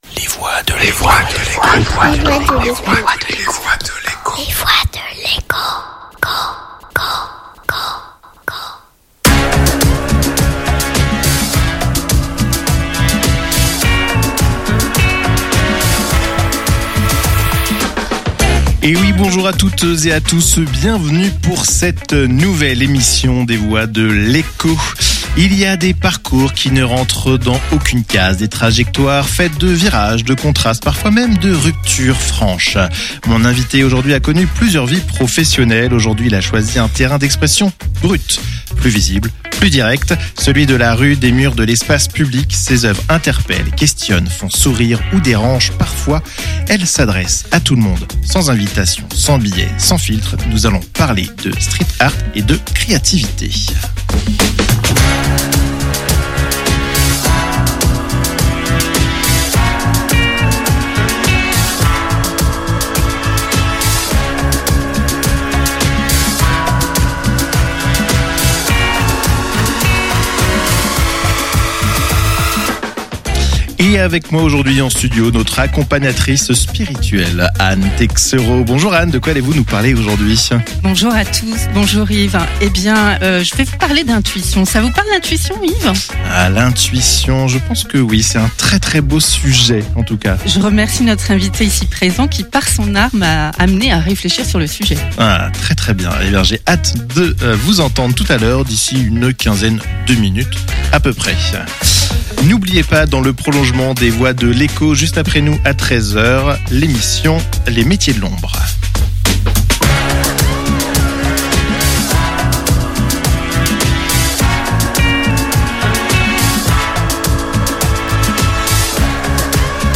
Quand l’art sort des cadres : street art, liberté et entrepreneuriat. Découvrez le replay de l'entretien